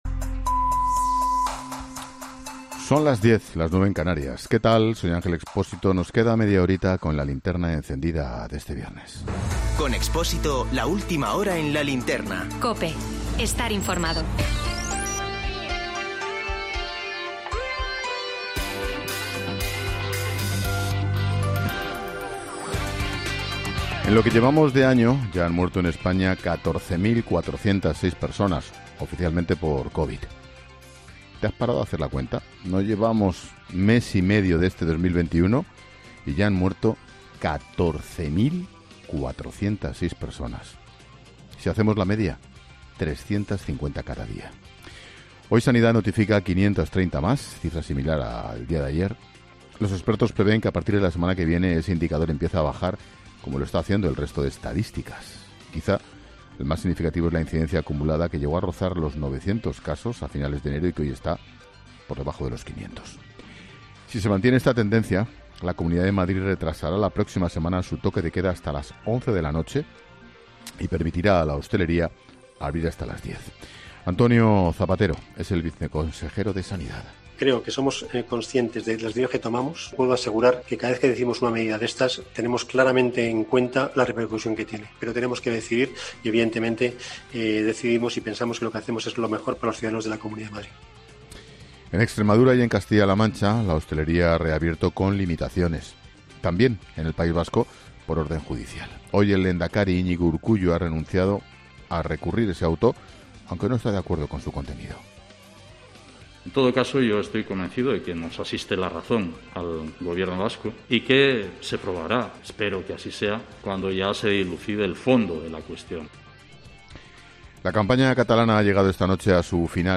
AUDIO: Monólogo de Expósito.